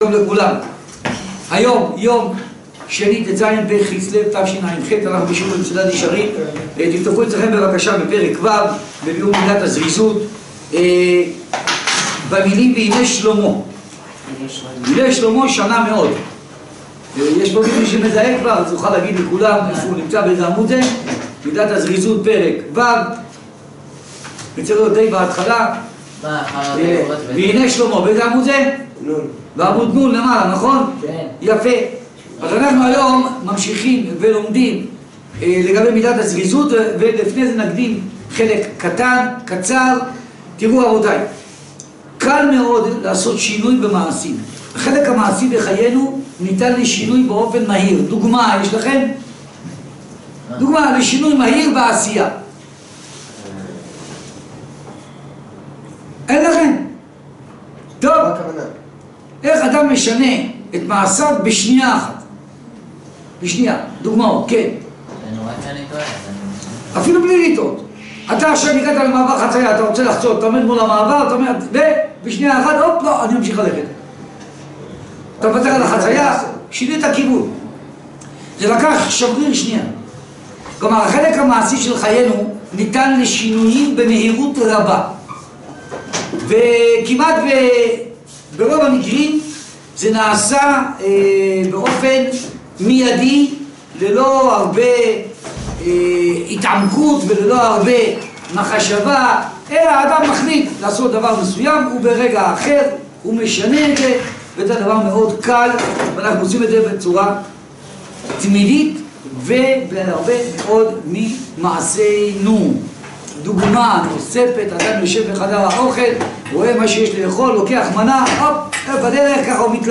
שיעורי מידות ומוסר